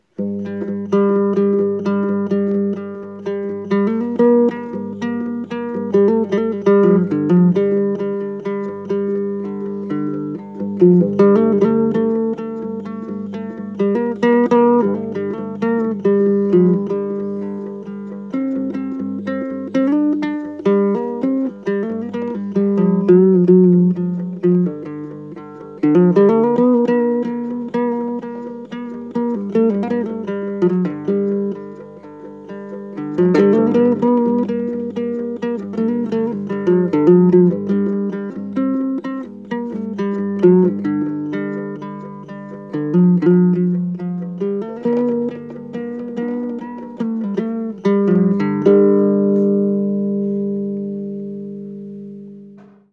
Walking Blues Guitar